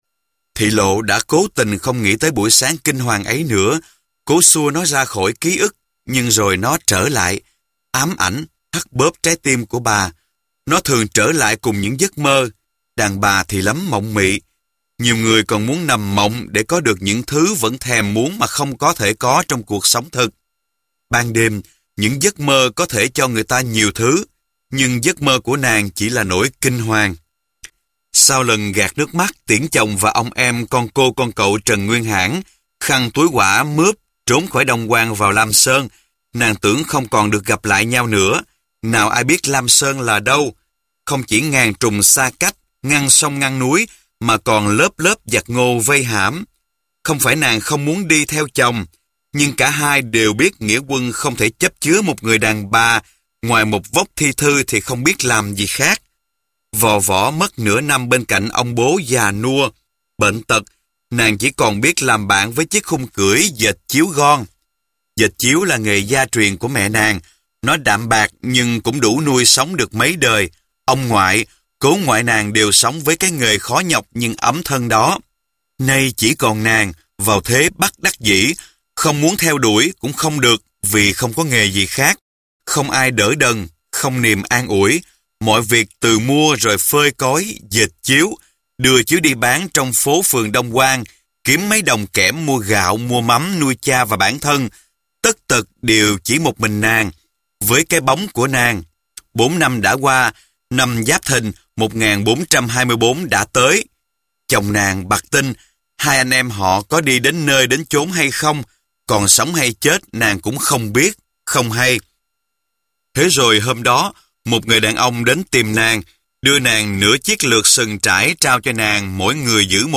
Sách nói Hội Thề - Nguyễn Quang Thân - Sách Nói Online Hay